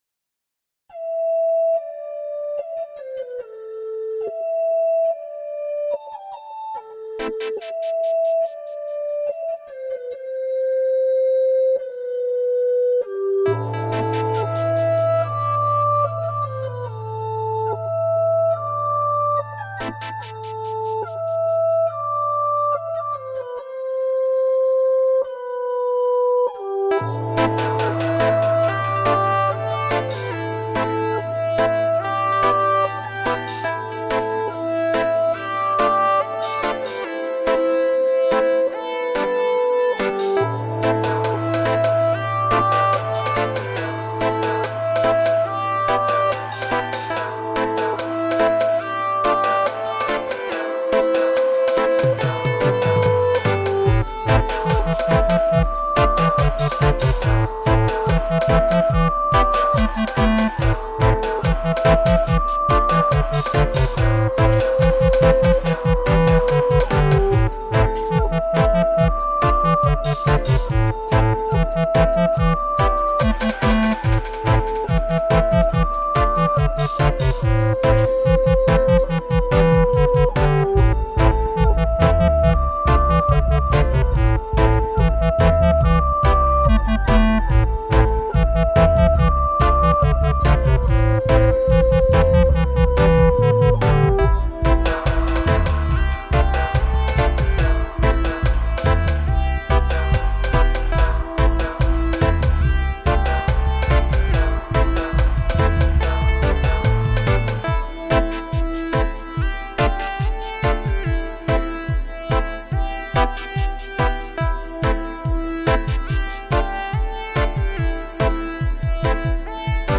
Big instrumental Tune!!